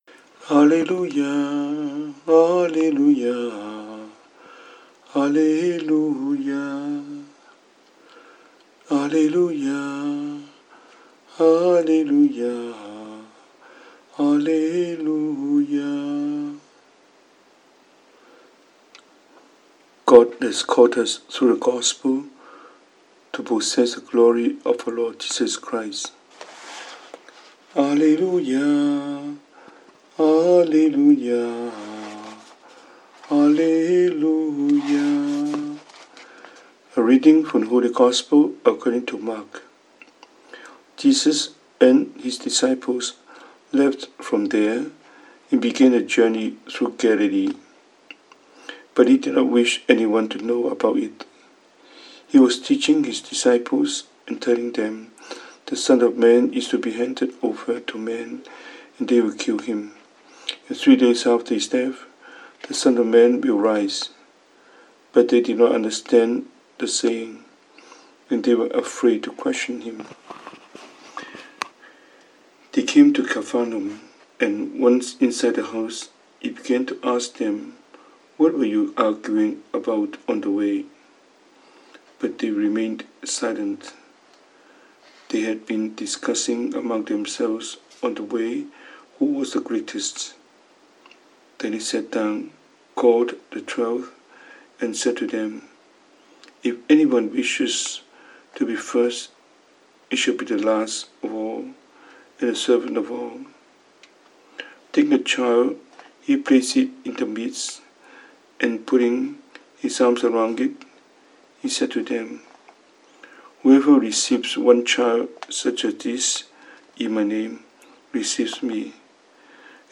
神父講道